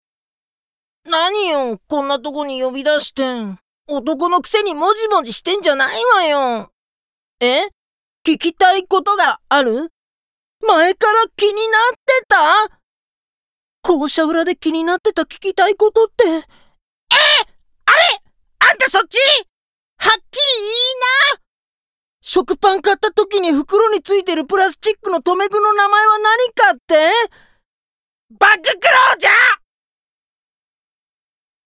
ボイスサンプル ＜高い声の少年＞
4_高い声の少年.mp3